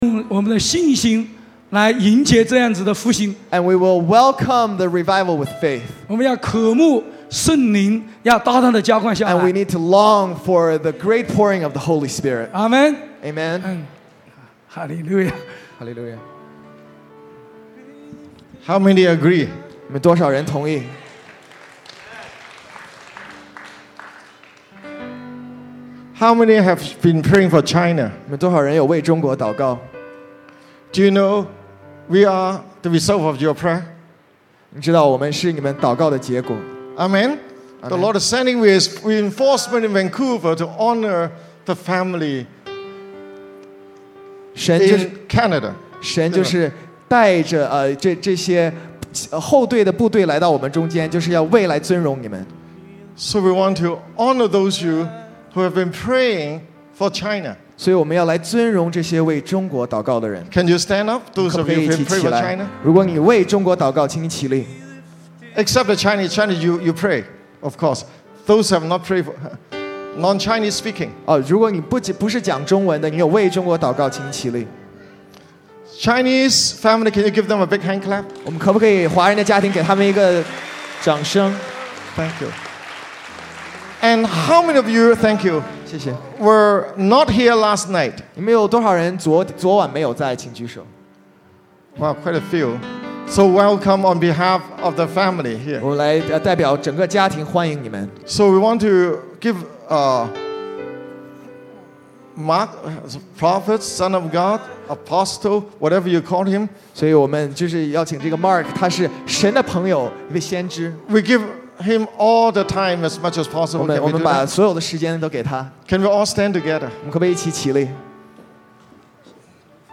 城市复兴特会（2）